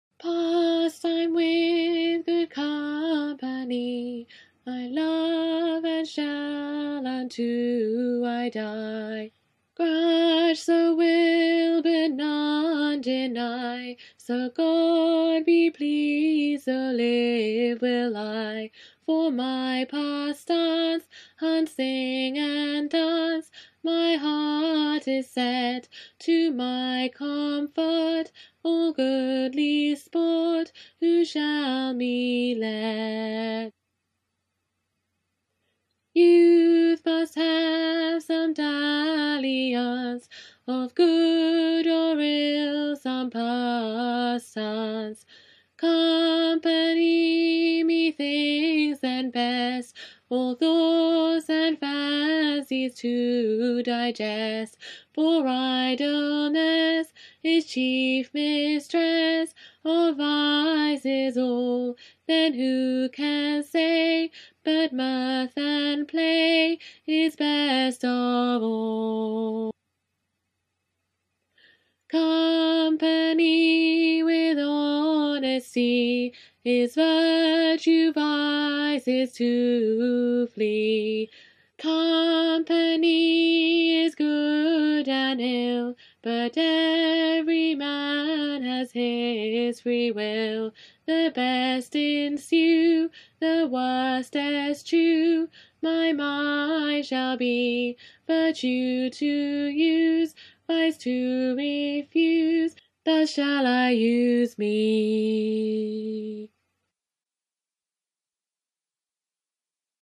CONTRALTI